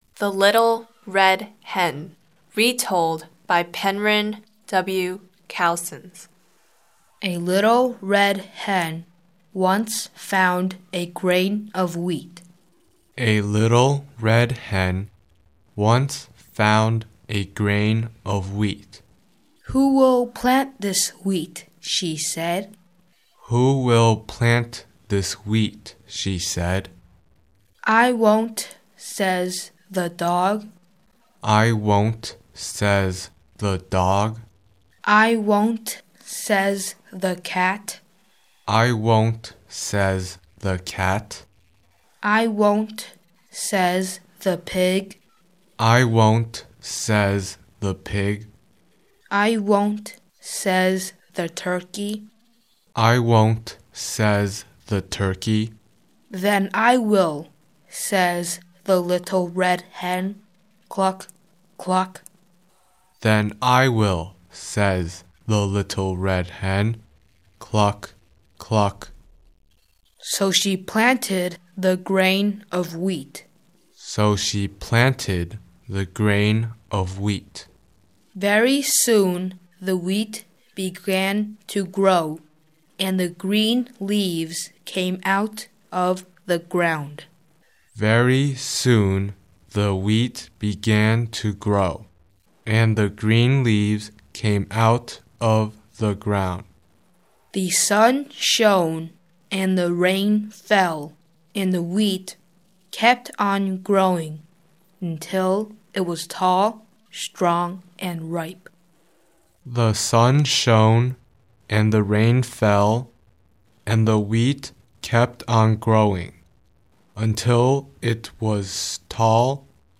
English beautiful reciting poems http